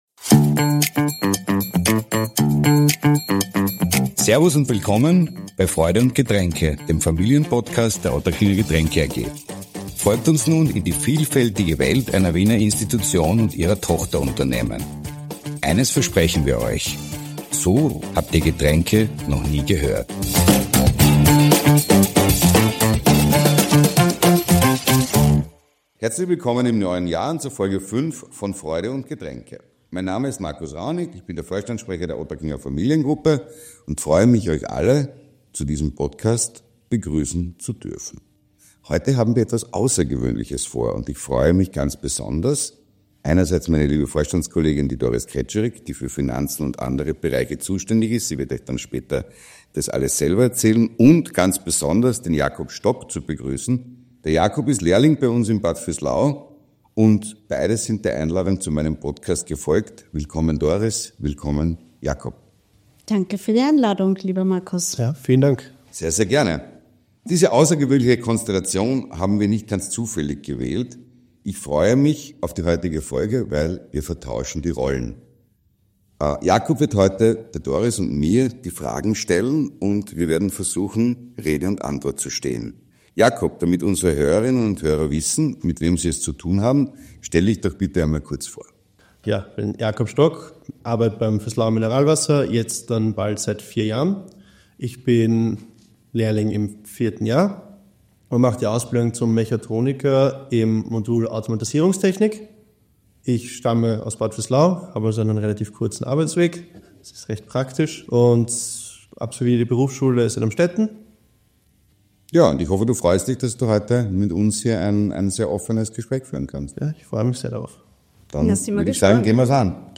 Lehrling trifft Führung: Ein Gespräch auf Augenhöhe ~ Freude und Getränke Podcast